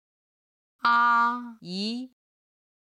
先生 (xiān sheng) ・・さん